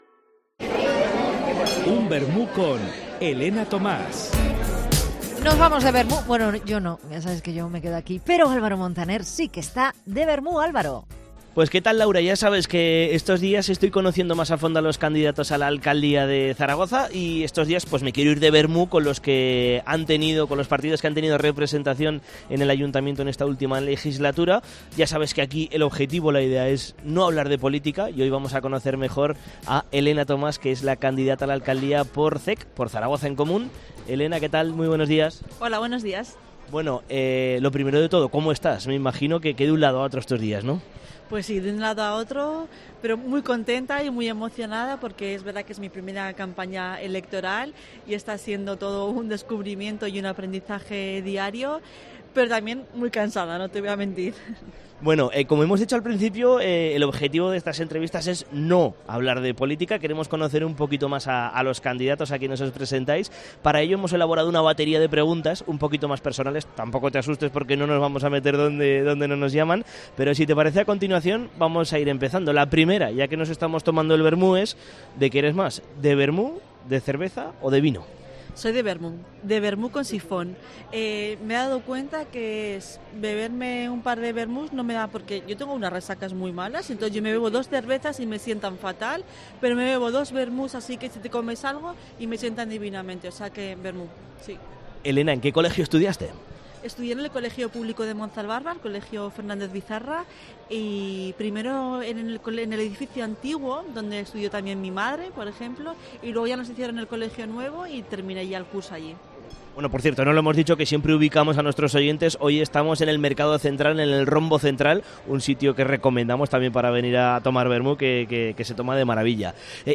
Tomamos el aperitivo con ella en el Rombo Zentral del Mercado Central y esto es lo que nos cuenta.